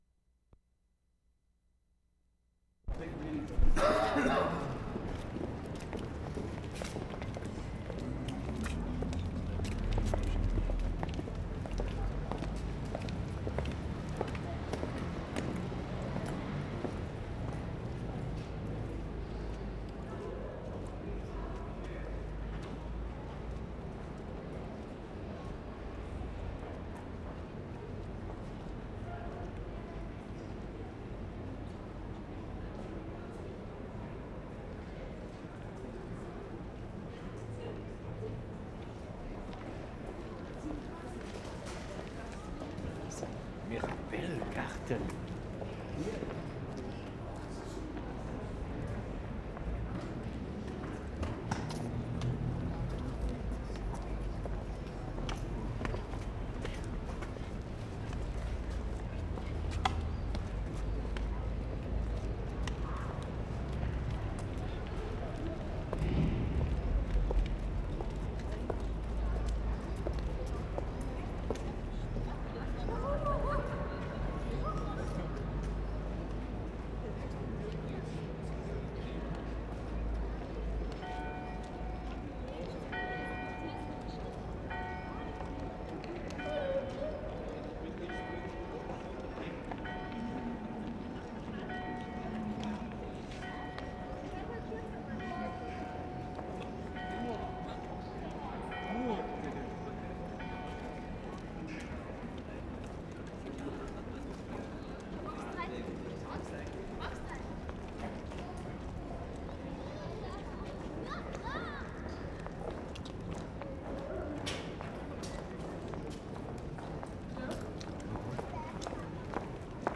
Salzburg, Austria March 16/75
SUNDAY CHURCH BELLS (CATHEDRAL) from Domplatz (noon) amidst ambience of footsteps, beginning with smaller bells.
mark * child passing by, imitating sound of nearby bell [2:20], followed by cathedral bell ringing noon. [2:40]
mark * more bells. [5:19]
3. Good footstep ambience, isolated voices of passers-by. Note threshold shift when bells end. A good sequence of bells in context.